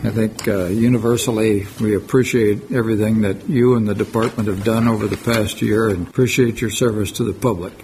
During the meeting, Manhattan Mayor and Law Board Chair Mike Dodson shared his appreciation for the work Butler has done.